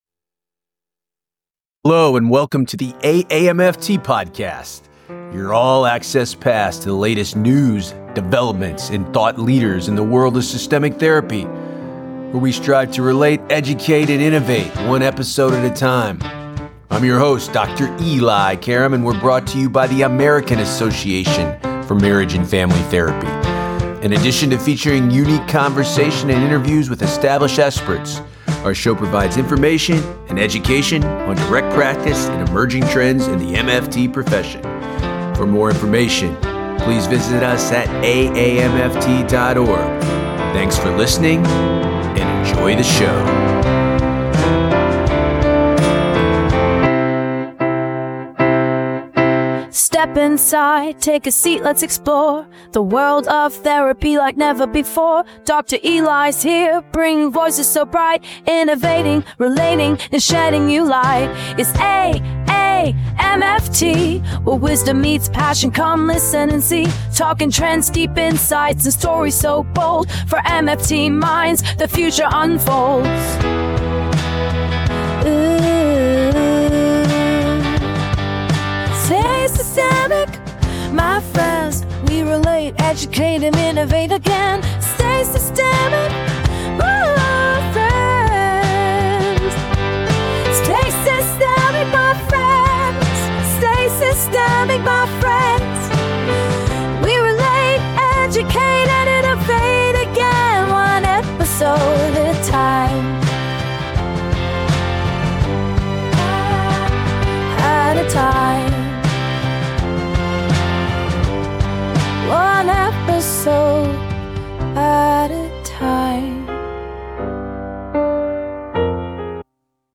The episodes explore topics that relationship-based therapists care about, and features unique conversations and interviews with established experts. The show provides information and education on direct practice and emerging trends in the MFT profession.